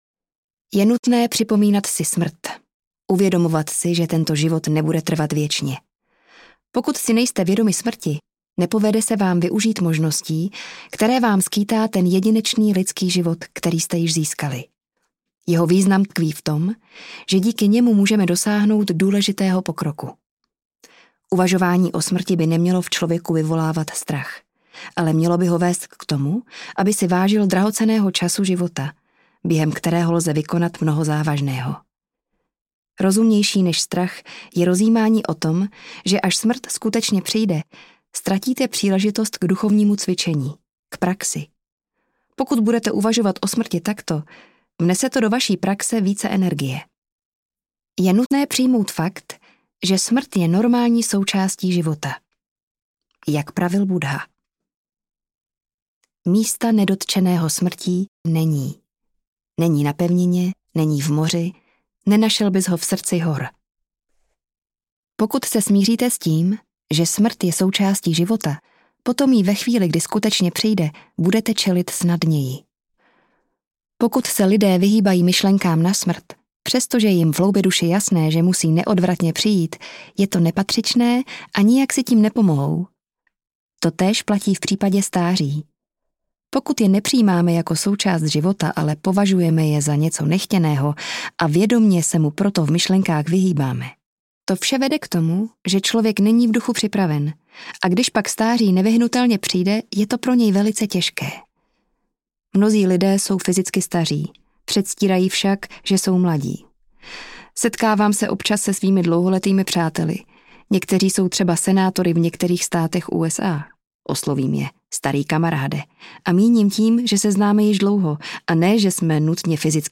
Rady jak umírat a žít lepší život audiokniha
Ukázka z knihy
rady-jak-umirat-a-zit-lepsi-zivot-audiokniha